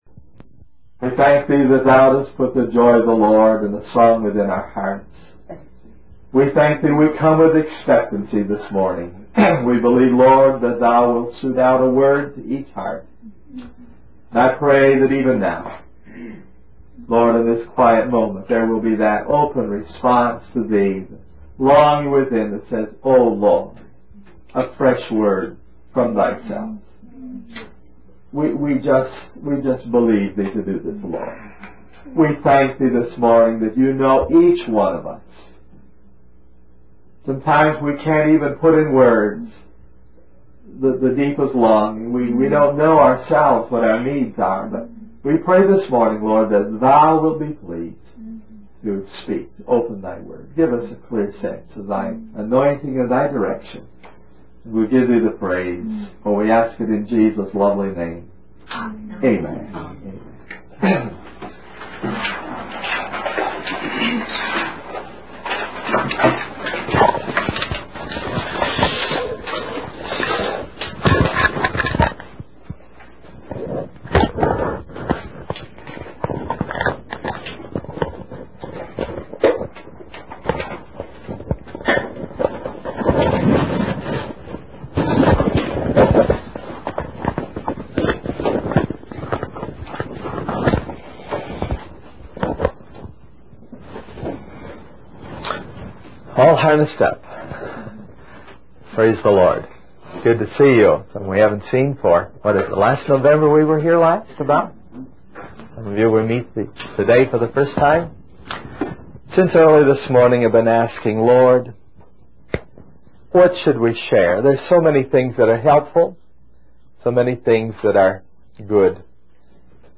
In this sermon, the speaker emphasizes the importance of spiritual reality over mere religious practices. He highlights the tendency for people to focus on urgent tasks and externalities, rather than prioritizing the deeper, spiritual aspects of their faith.